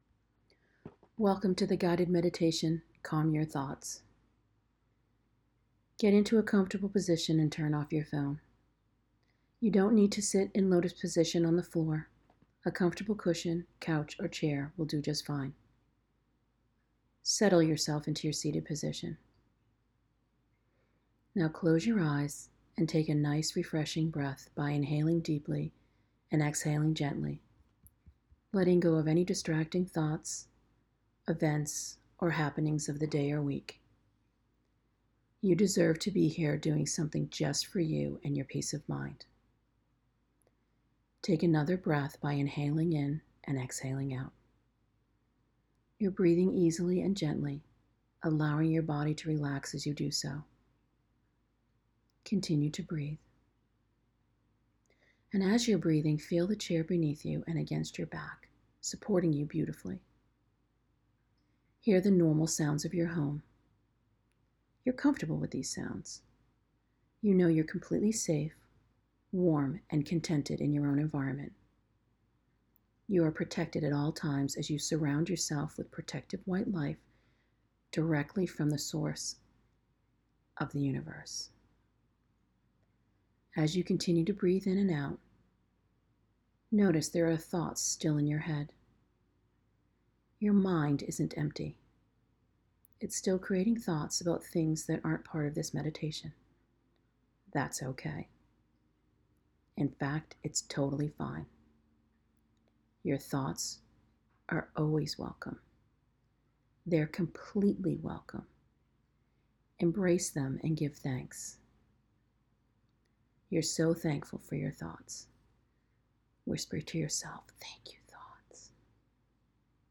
4.-Meditation-Calm-Your-Thoughts2.m4a